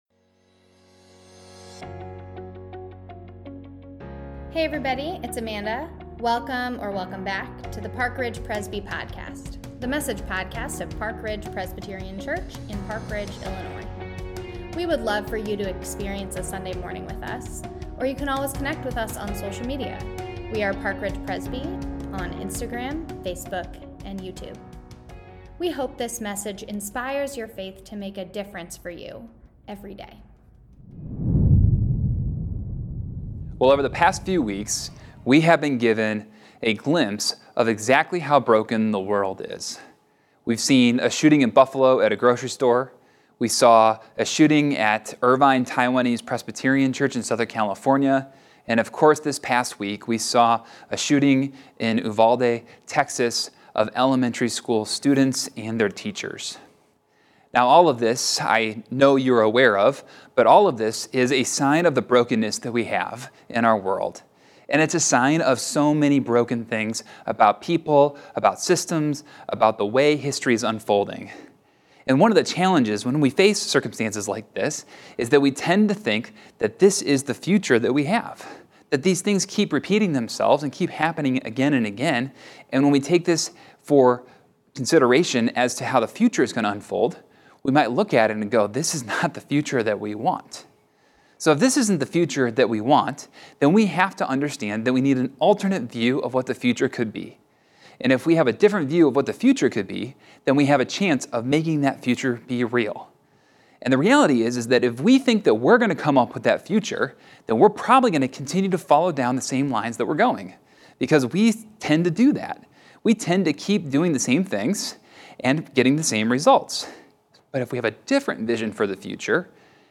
Online Church | Sunday May 29 Worship at Park Ridge Presby